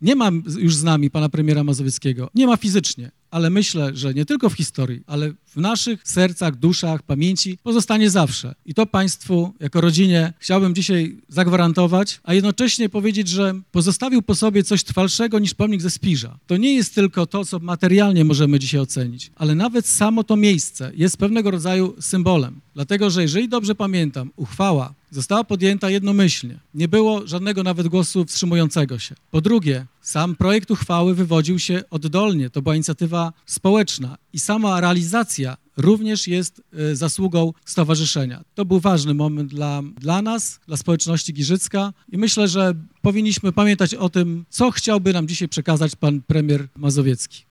W piątek (14.09.18) w pobliżu Urzędu Miejskiego odbyła się uroczystość odsłonięcia skweru imienia byłego premiera.
– Na społeczną inicjatywę stowarzyszenia Wolne Miasto Giżycko odpowiedzieli radni miejscy z Giżycka, którzy jednogłośnie podjęli uchwałę dotyczącą nadania skwerowi imienia premiera Tadeusza Mazowieckiego – mówi Wojciech Iwaszkiewicz, burmistrz grodu nad Niegocinem.